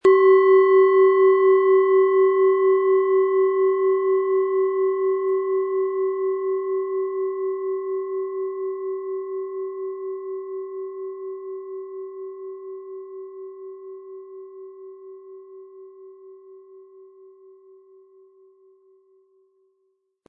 Von Hand hergestellte Klangschale mit dem Planetenton Biorhythmus Geist.
Der kräftige Klang und die außergewöhnliche Klangschwingung der traditionellen Herstellung würden uns jedoch fehlen.
Mit dem beigelegten Klöppel können Sie je nach Anschlagstärke dominantere oder sanftere Klänge erzeugen.